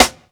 SNARE_COLD_HEART.wav